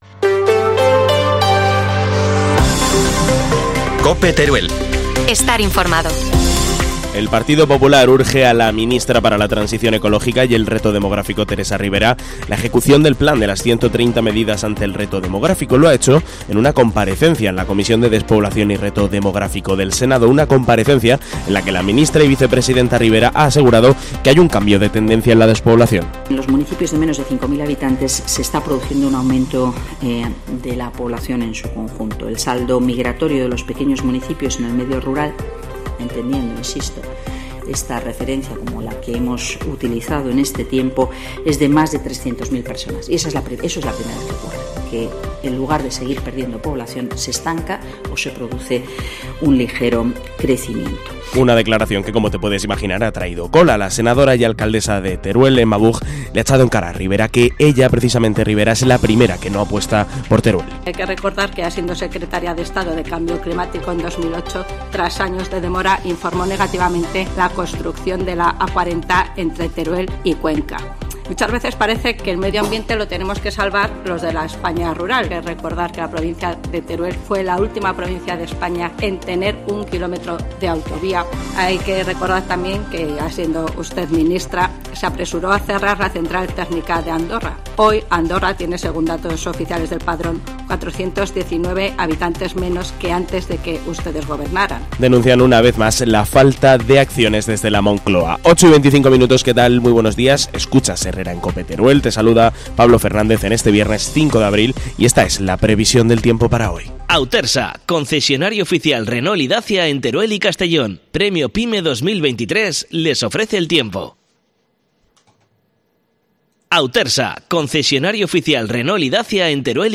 AUDIO: Titulares del día en COPE Teruel